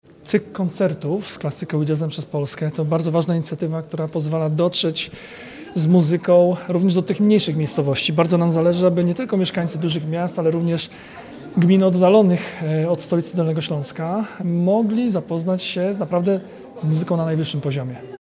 – Dostęp do kultury nie musi być ograniczony do dużych miast – mówi Jarosław Rabczenko, członek zarządu województwa dolnośląskiego.